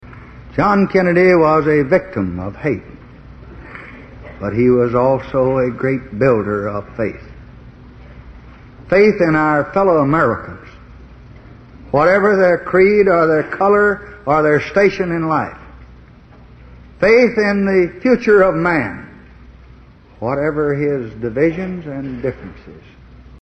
Tags: Lyndon Baines Johnson Lyndon Baines Johnson speech State of the Union State of the Union address President